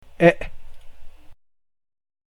Yet, this one has a sound that is distinctive, and the vowel wears a "hat" (a circumflex) to make it unmistakable.
This vowel has the same sound as the letter E in the English word WRECK.